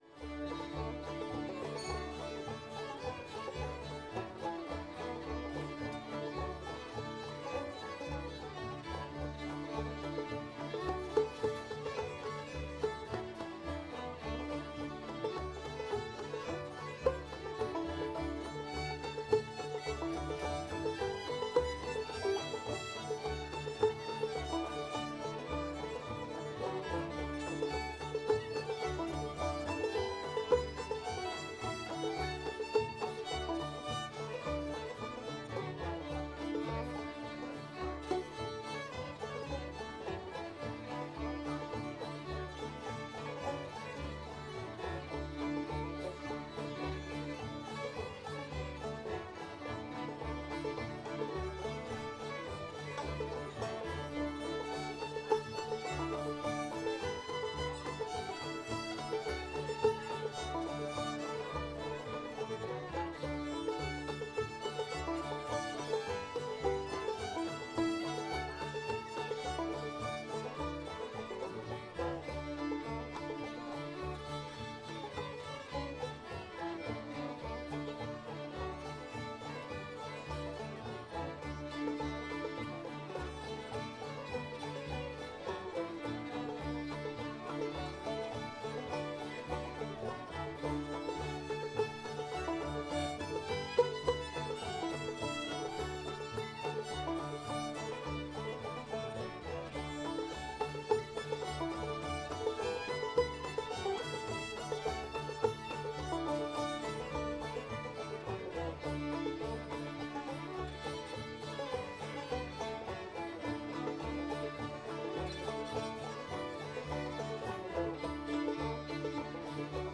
duck river [D]